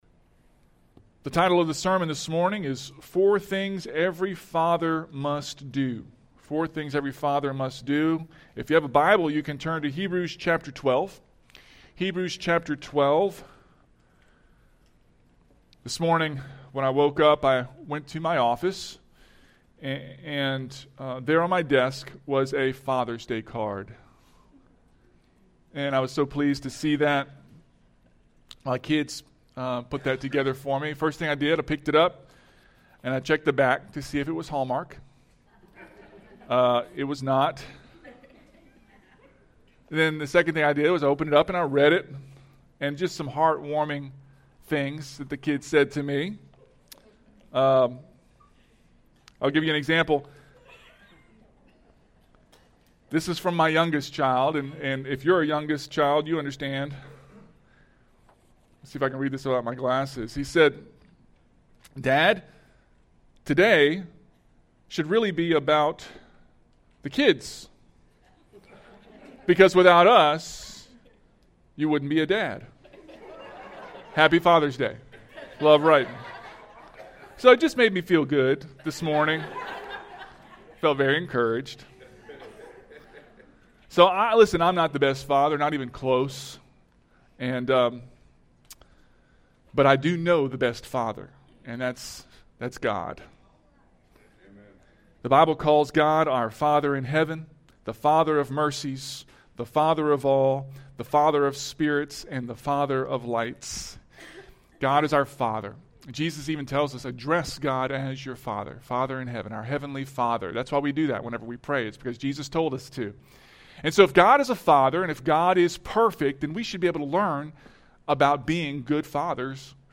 Stand-Alone Sermons